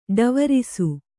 ♪ ḍavarisu